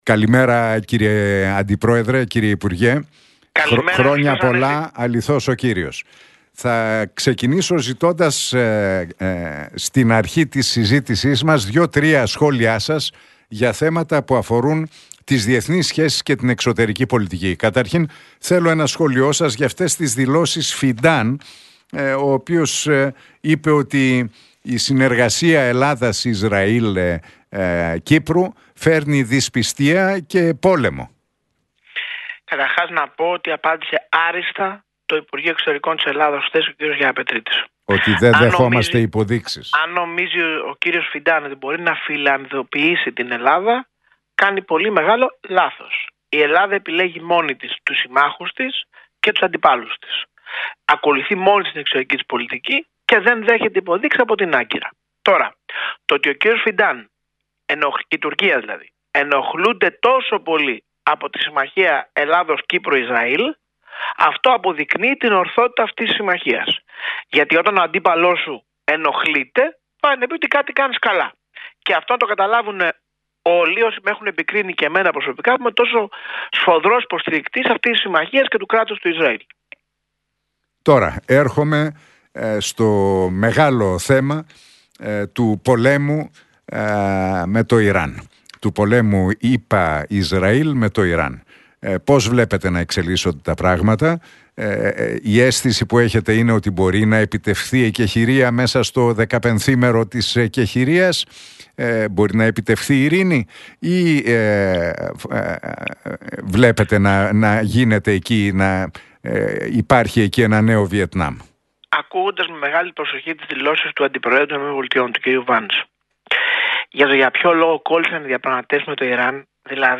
Για τις διεθνείς και εσωτερικές εξελίξεις μίλησε μεταξύ άλλων ο υπουργός Υγείας, Άδωνις Γεωργιάδης στον Realfm 97,8 και την εκπομπή του Νίκου Χατζηνικολάου.